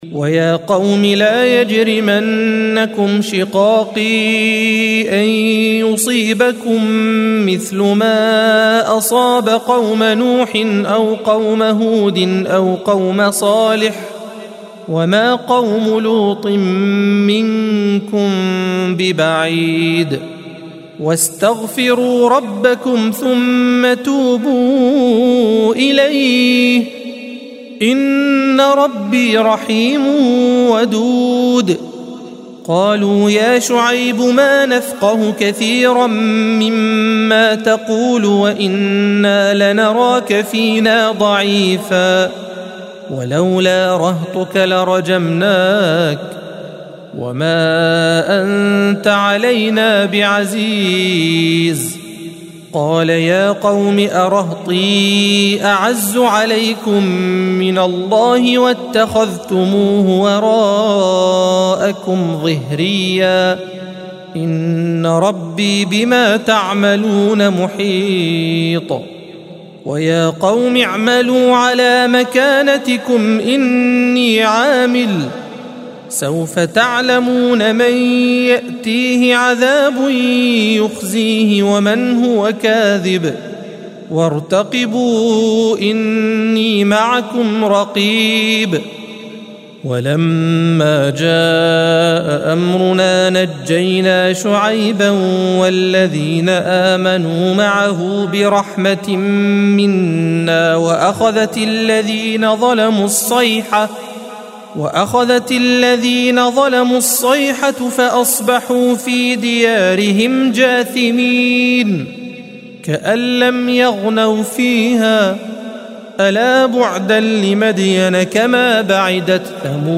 الصفحة 232 - القارئ